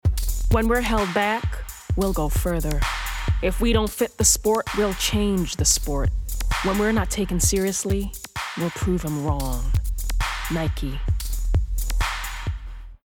Confident/Engaging/Soothing
Nike (American accent)